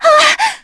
Cassandra-Vox_Damage_02_kr.wav